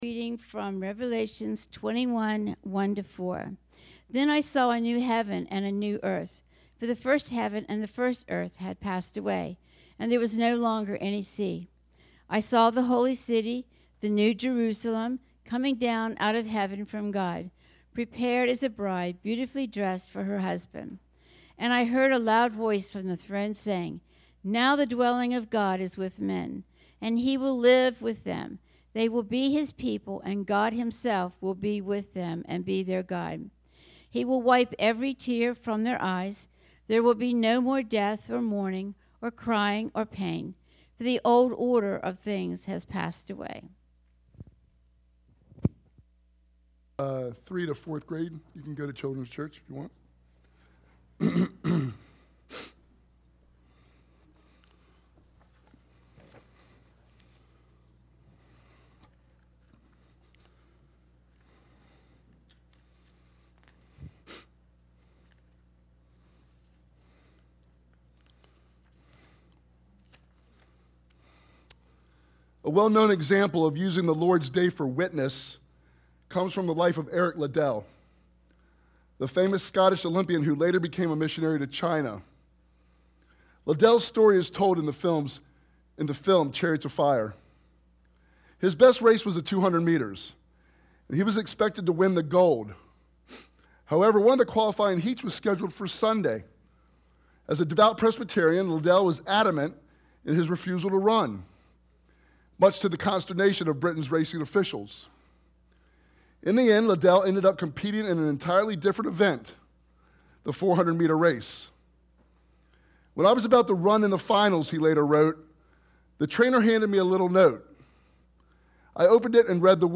Idaville Church » Sermons